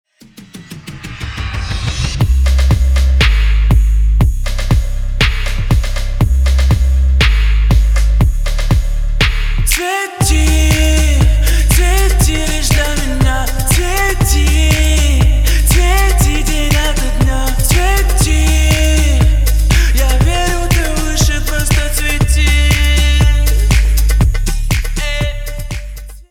• Качество: 320, Stereo
мужской вокал
красивый мужской голос
русский рэп